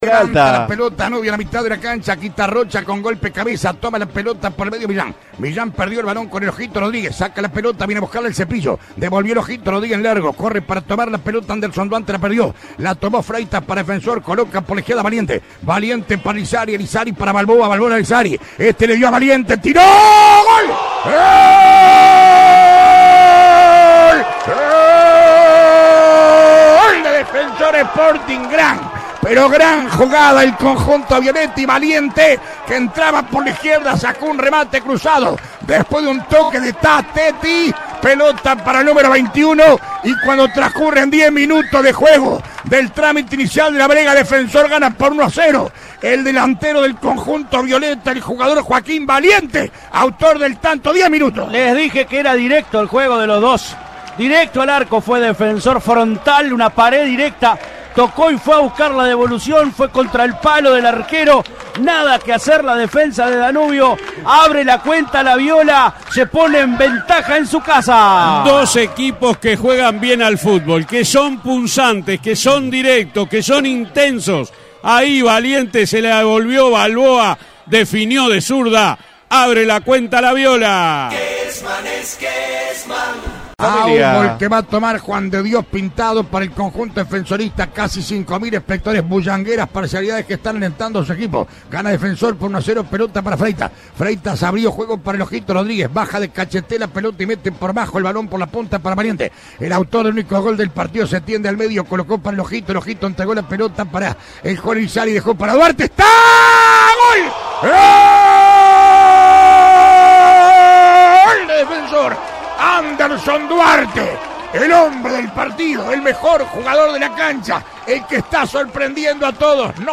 LOS GOLES RELATADOS POR ALBERTO KESMAN